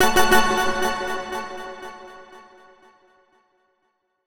Pauze Game Arcade.wav